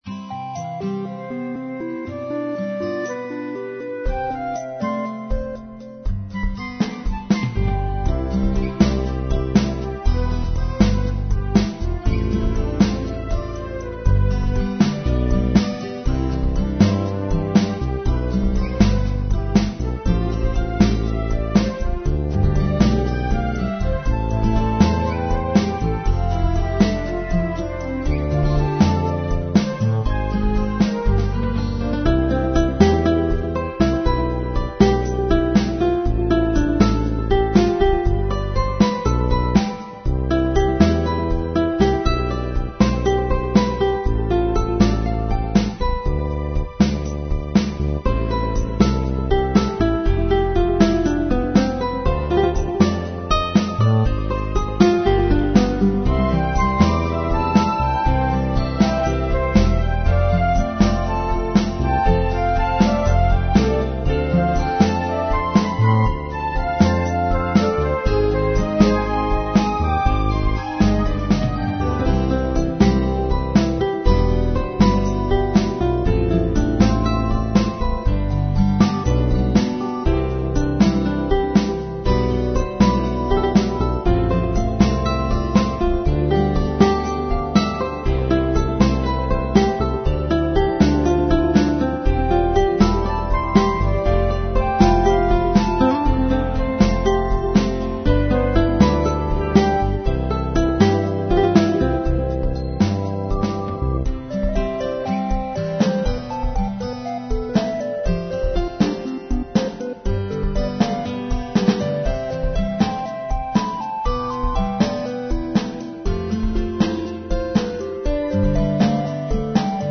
Relaxed Instrumental Pop with feel of Childs Innocence